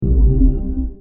booglerbass.mp3